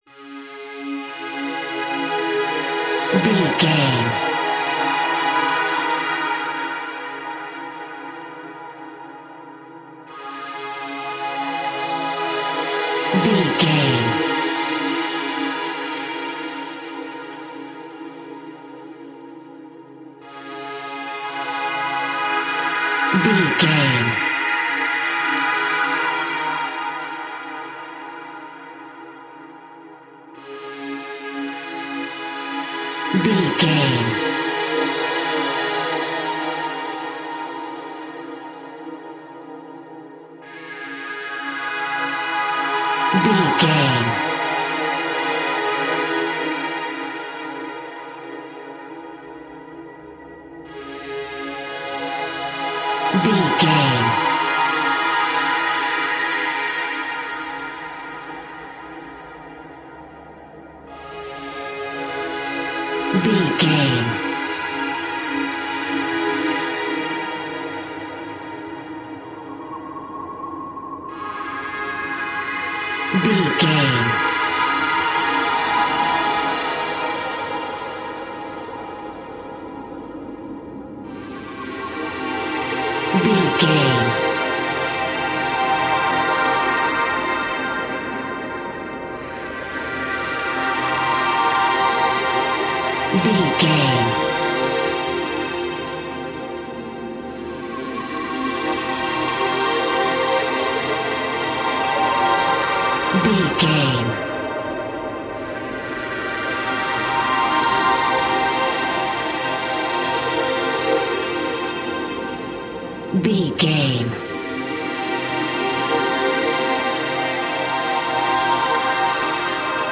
Aeolian/Minor
C#
ominous
dark
eerie
synthesizer
Horror Pads
horror piano
Horror Synths